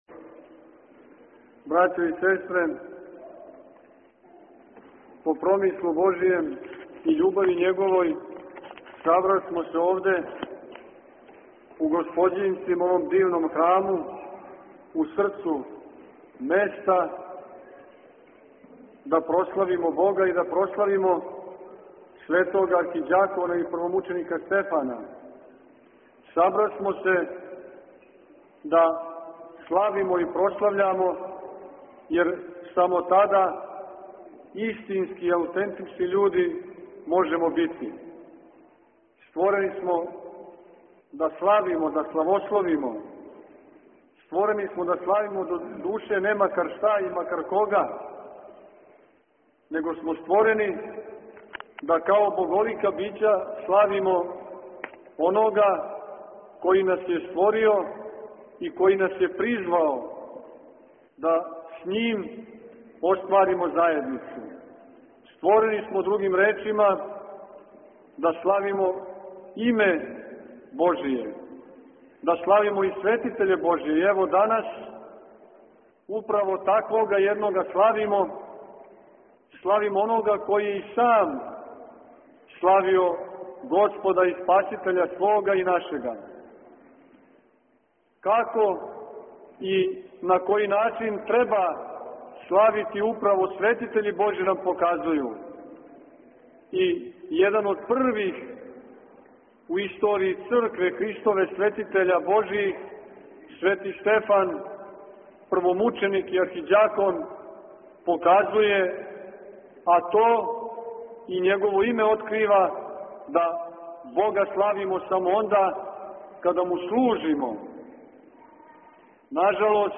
• Беседа Епископа Порфирија: